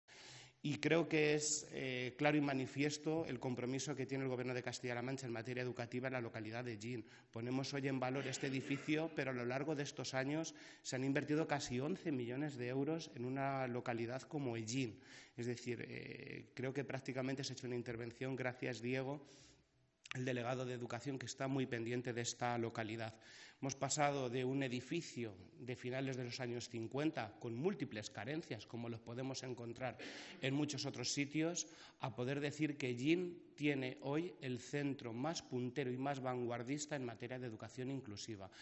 Consejería de Educación, Cultura y Deportes Miércoles, 12 Noviembre 2025 - 1:15pm El consejero de Educación, Cultura y Deportes, Amador Pastor, durante la inauguración, a cargo del presidente de Castilla-La Mancha, Emiliano García-Page, del nuevo Centro de Educación Especial de Hellín, ha señalado que el Gobierno regional invierte casi 11 millones de euros en los últimos años para mejorar los servicios educativos de la localidad albaceteña de Hellín. pastor_-_inversiones_en_hellin.mp3 Descargar: Descargar